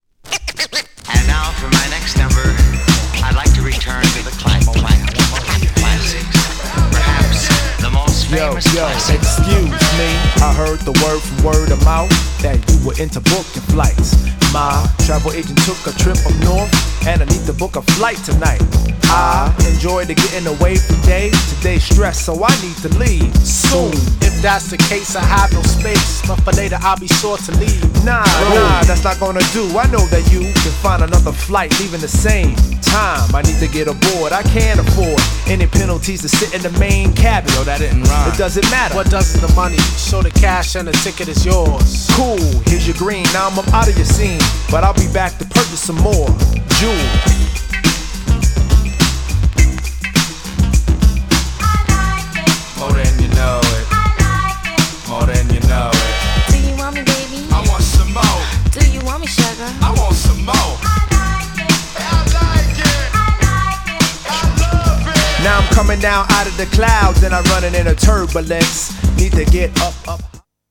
アングラ感溢れるDOPEなサウンドが目白押し!! 2枚組。
GENRE Hip Hop
BPM 91〜95BPM